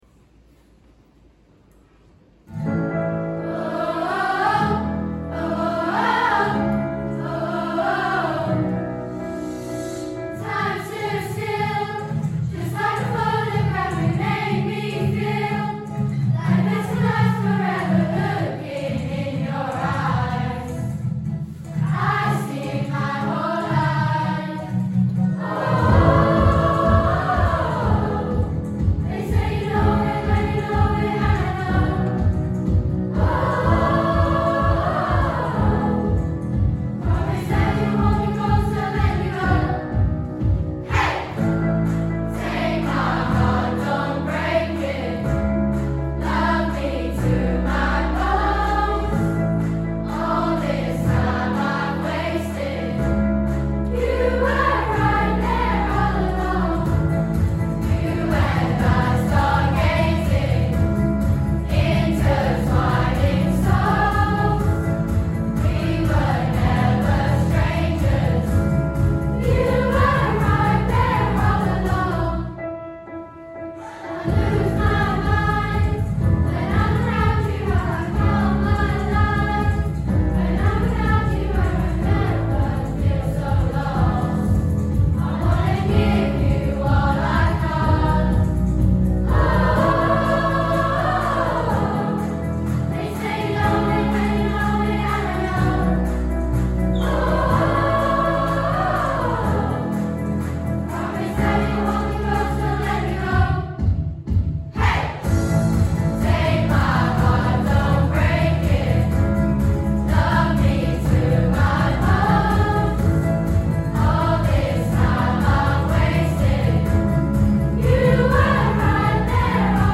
Stargazing | Chorus